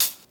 Boom-Bap Hat CL 60.wav